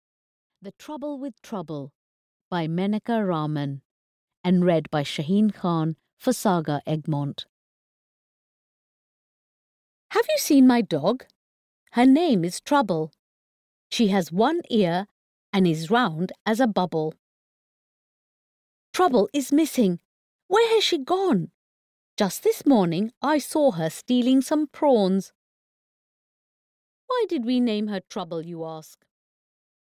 The Trouble with Trouble (EN) audiokniha
Ukázka z knihy